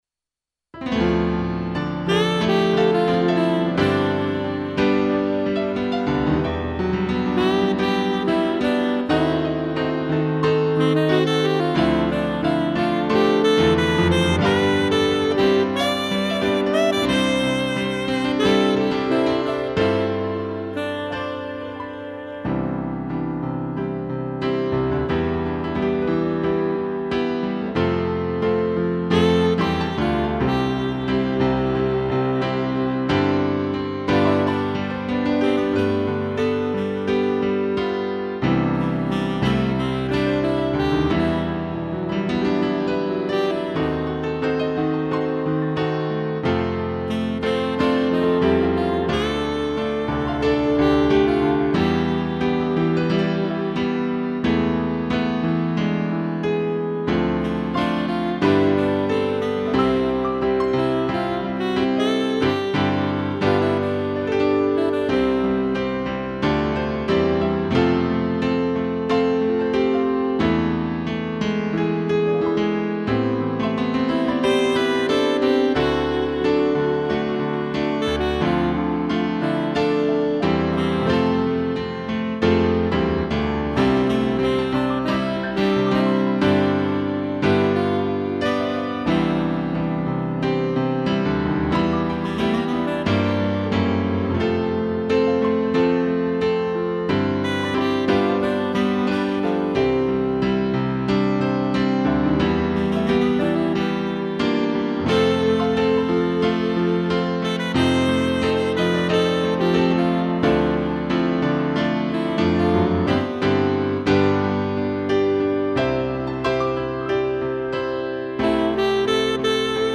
2 pianos e sax
(instrumental)